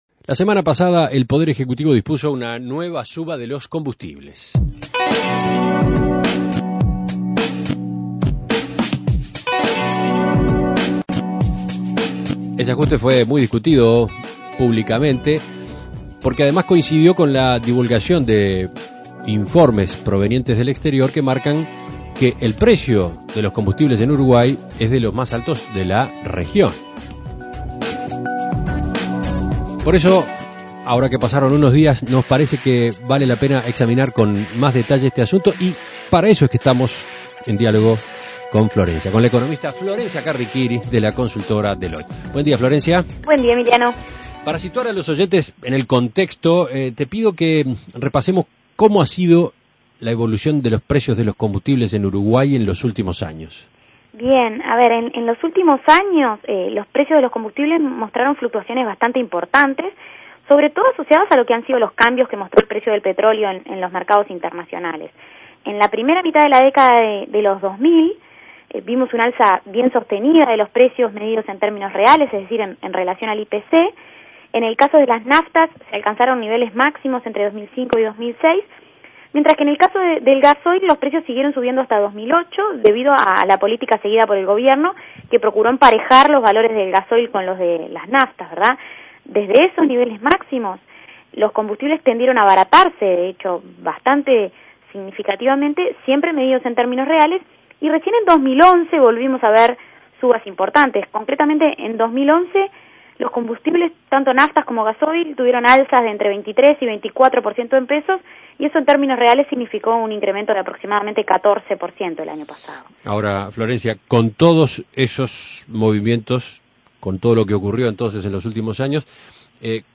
Análisis Económico Los precios de los combustibles en Uruguay